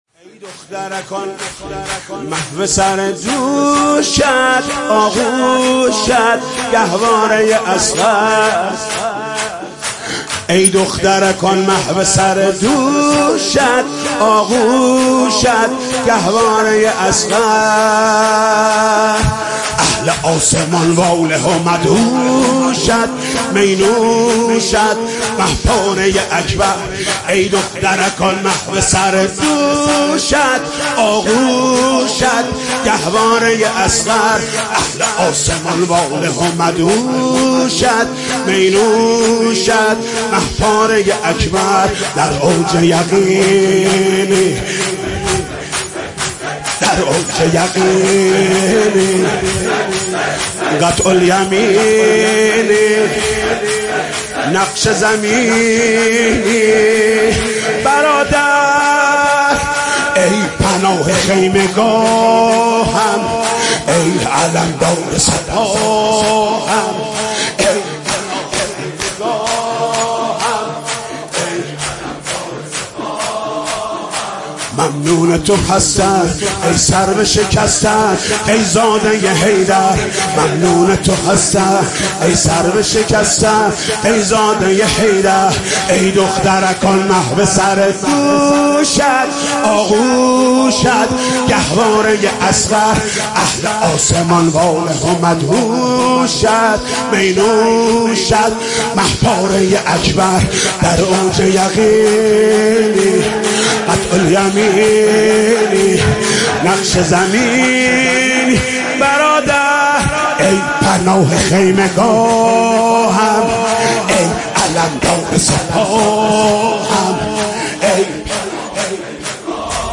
نوحه جديد
مداحی صوتی